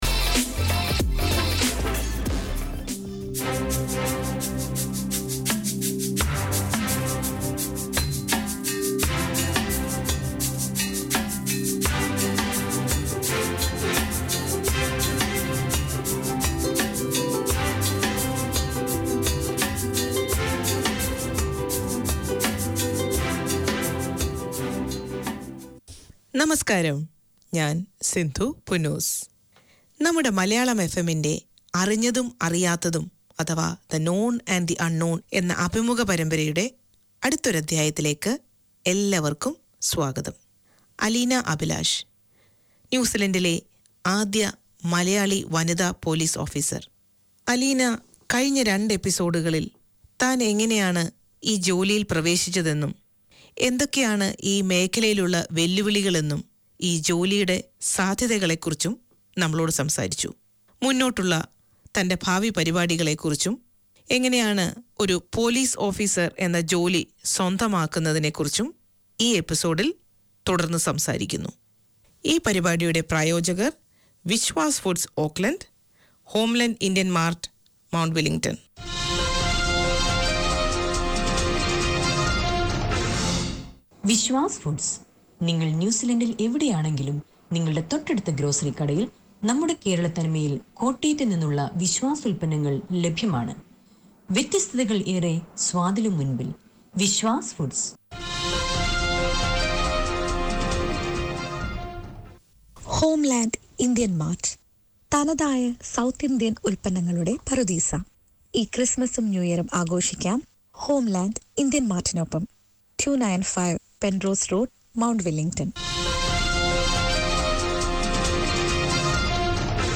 Manukau Indian FM is a registered charity whose vision is to connect communities with the Hindu, Sanatan, and Indian culture. The programme showcases the history, traditions and festivals of India and Fiji through storytelling and music, including rare Fiji Indian songs.